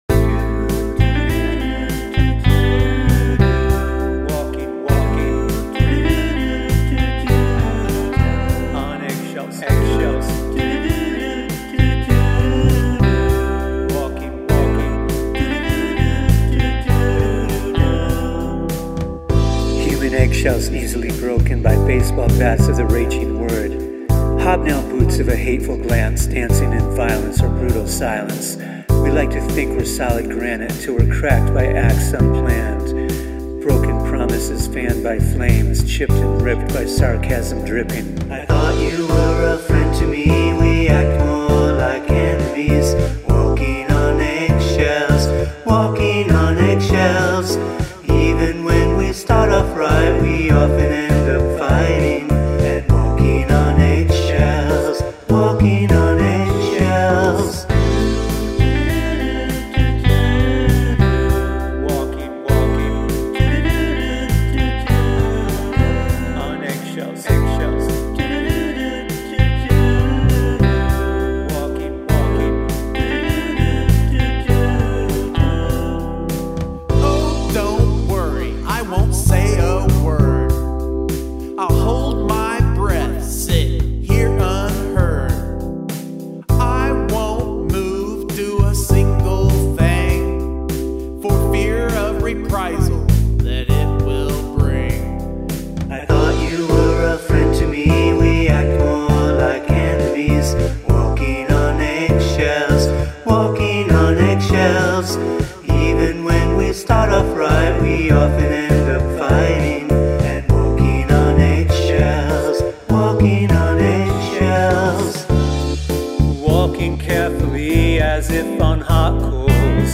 Guest spoken word